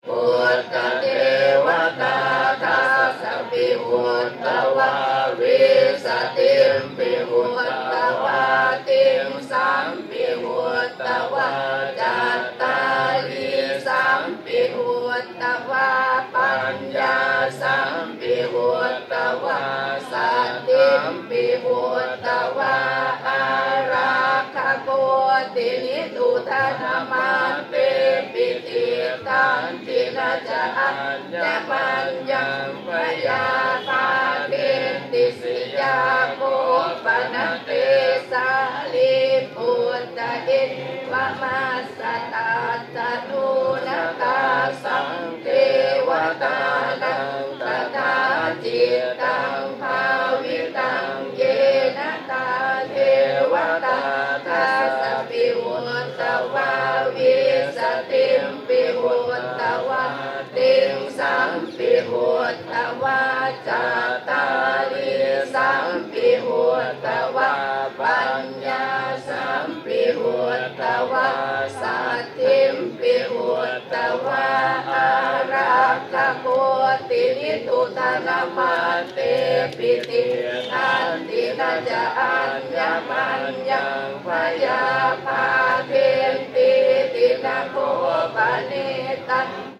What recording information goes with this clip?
Prayers in the temple, Bodhgaya Prayers at Bodhi Temple Bodhgaya. Stereo 48kHz 24bit.